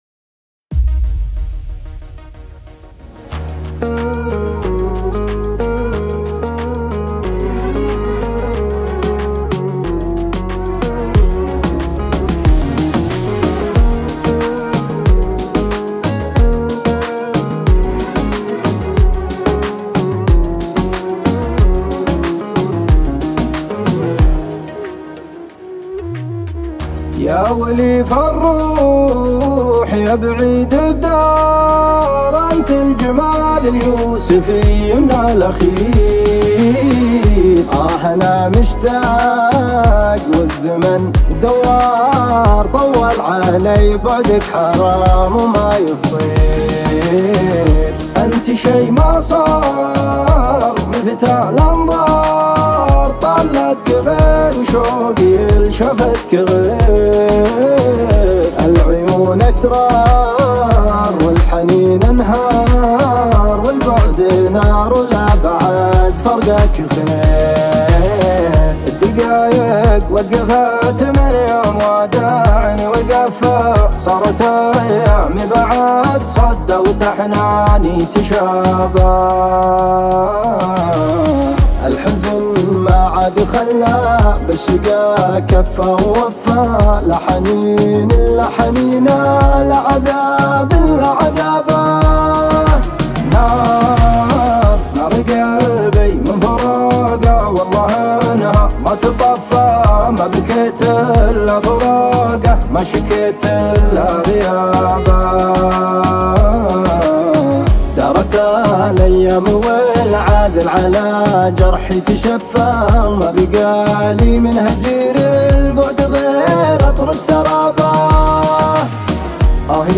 شيلات دويتو